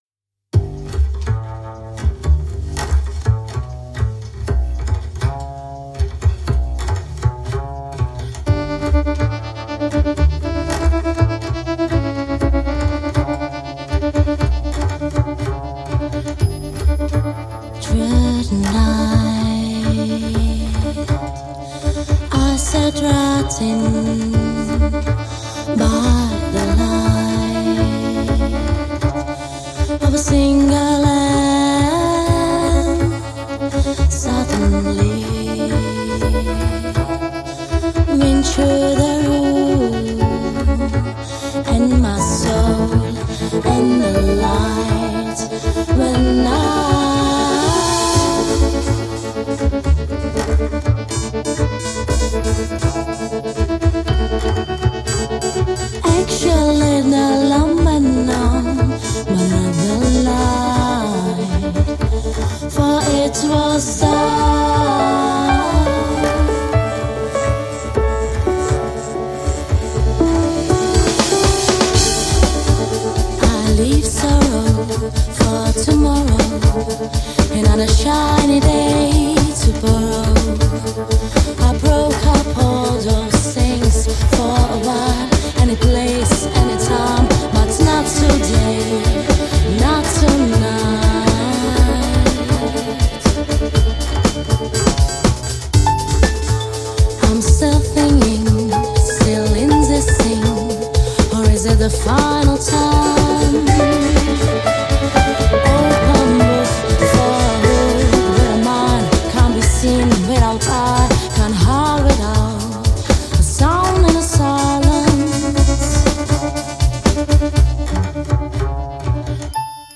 Žánr: Electro/Dance
svým sytým hlasem